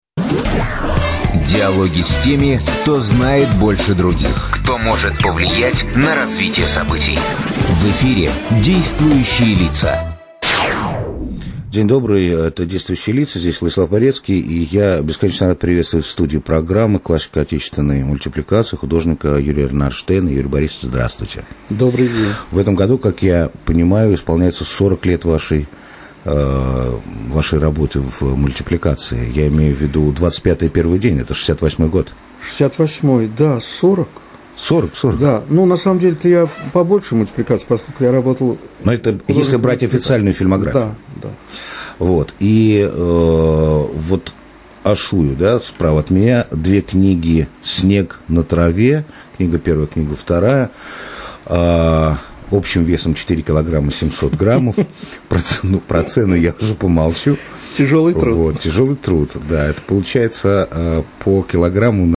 I opened the URL in WMP, used Audacity to record what the sound card was playing, then saved as MP3.